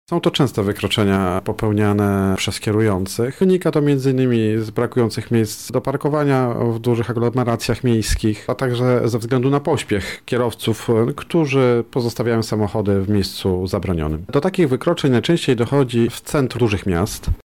Kary -mówi Młodszy Aspirant